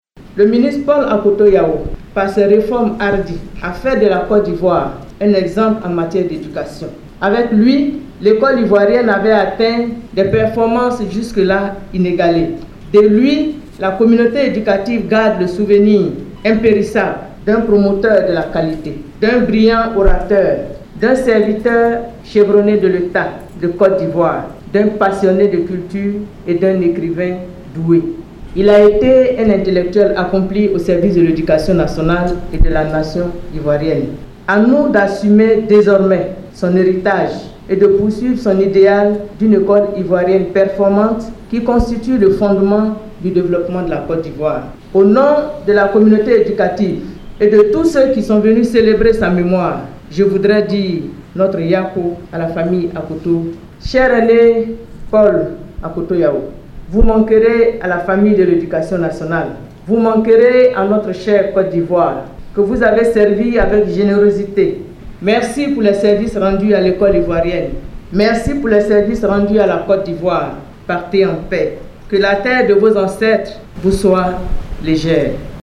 Prof. Mariatou Koné, Ministre de l’Education Nationale – Hommage à Paul Akoto Yao - Site Officiel de Radio de la Paix
C’était ce jeudi 06 avril 2023, au Centre National des Matériels scientifiques (CNMS) de Cocody, où le défunt a enseigné et où un amphithéâtre porte désormais son nom.